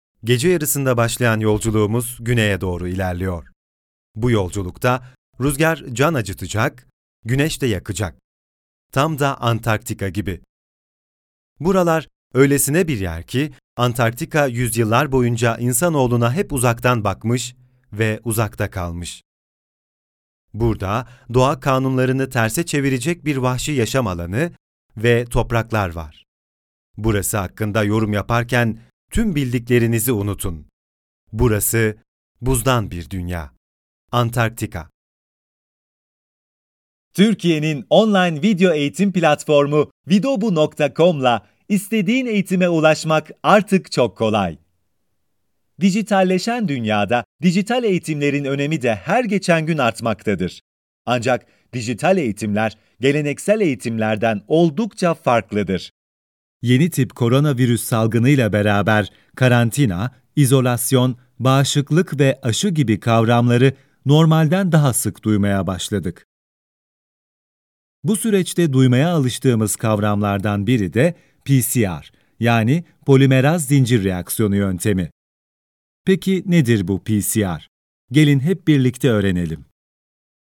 Turkish Male Voiceover
Middle Eastern, Turkish, Male, Home Studio, 20s-40s, Based in Turkey